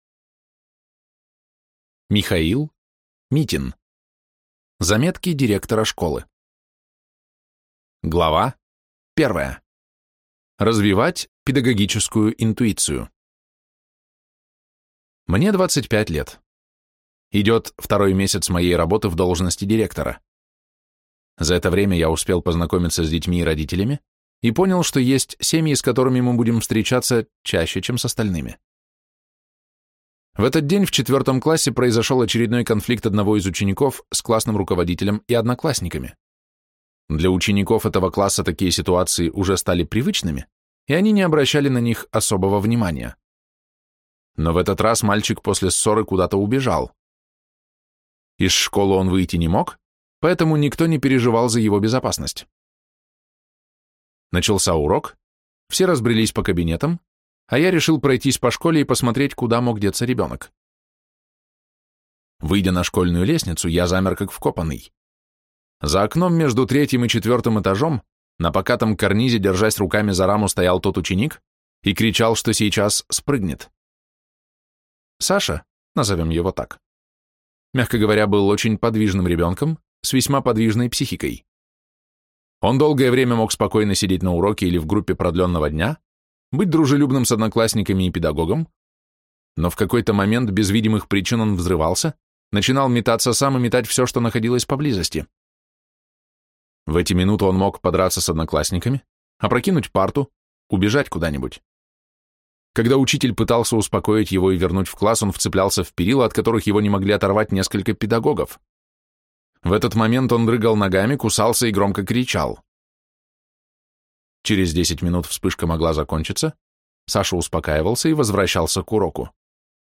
Аудиокнига Заметки директора школы | Библиотека аудиокниг